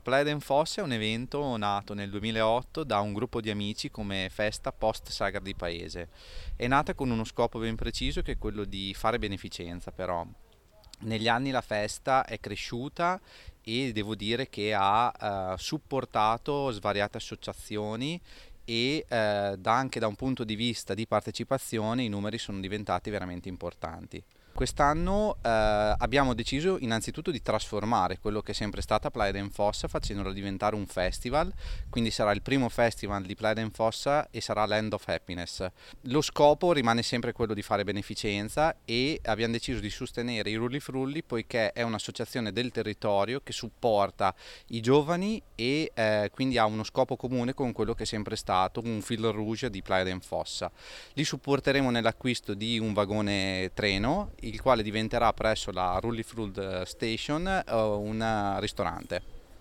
nella giornata di presentazione